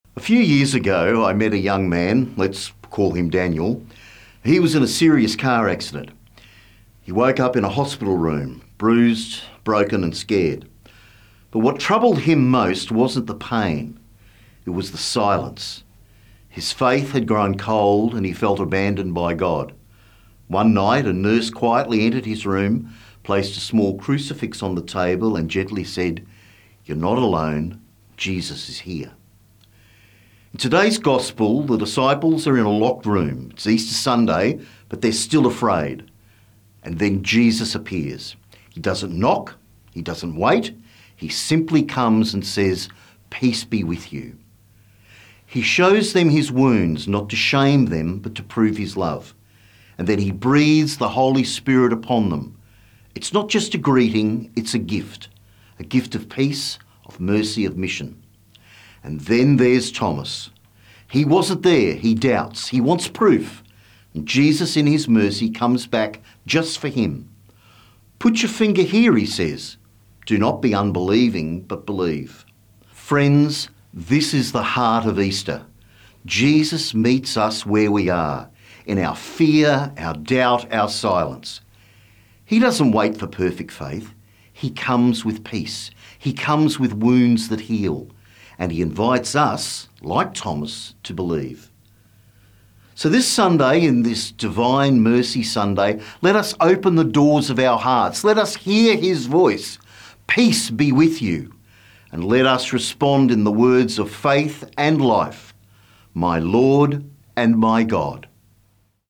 Archdiocese of Brisbane Second Sunday of Easter - Two-Minute Homily